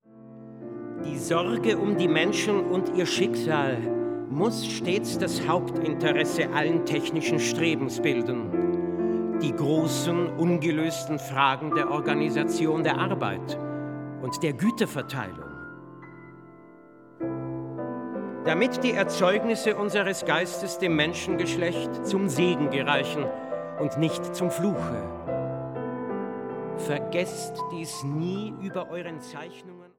Klavier
Werke für Sprecher und Klavier.